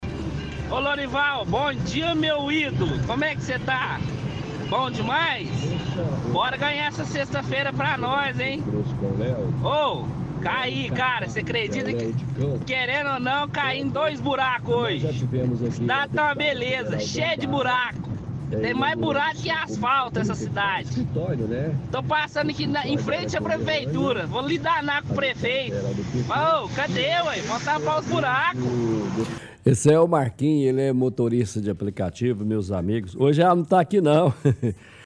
– Ouvinte relata que caiu em dois buracos e reclama que a cidade tem mais buraco do que asfalto.